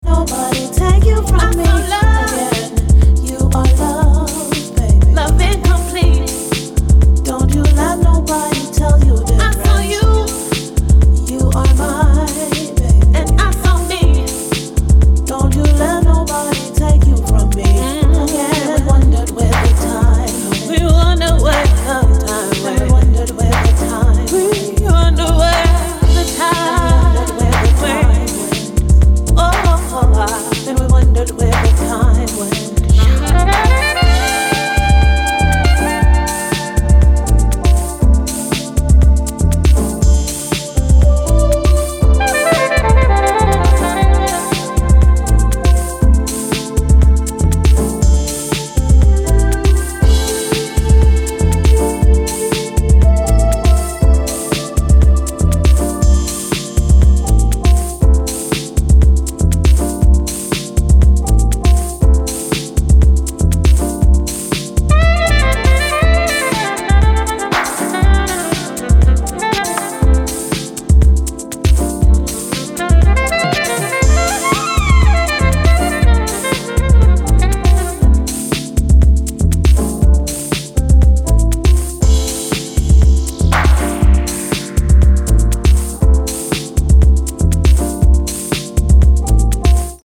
ジャジーなサックスも交えながらしっとりディープ・ウォームなヴォーカル・ハウスを展開しています。